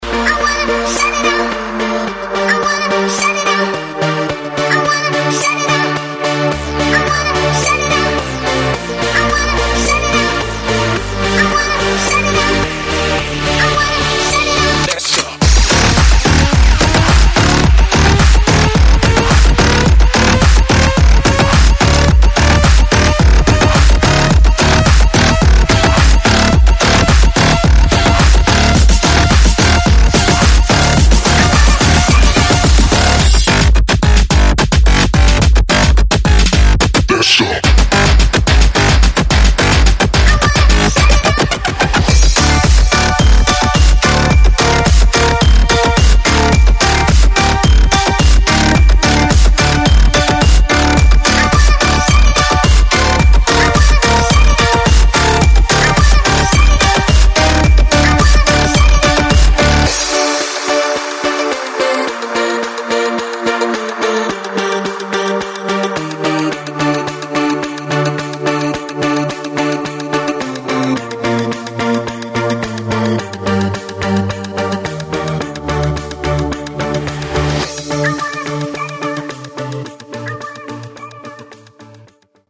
• Качество: 128, Stereo
веселые
саундтреки
клубняк